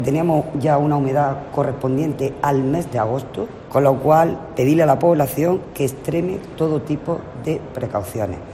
Delegada de la Junta en Almería, Aránzazu Martín